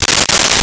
Tear Wrapper
Tear Wrapper.wav